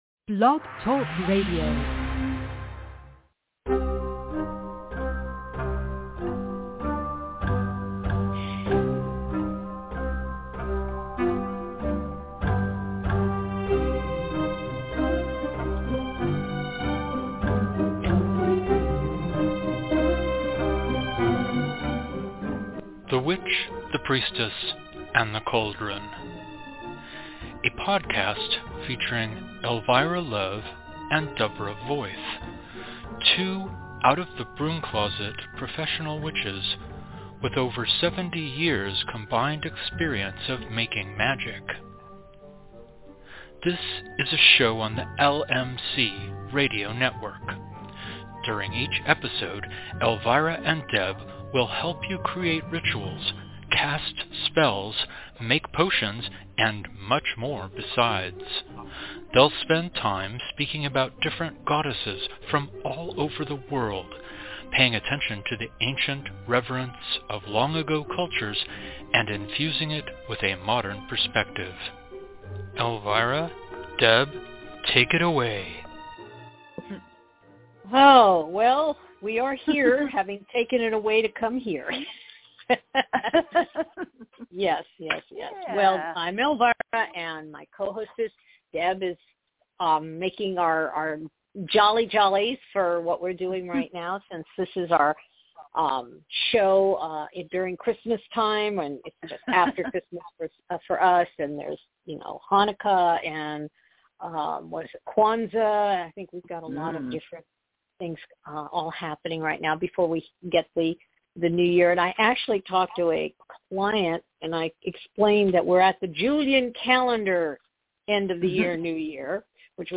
A new episode of The Witch, The Priestess, and The Cauldron air LIVE every Thursday at 4pm PT / 7pm ET!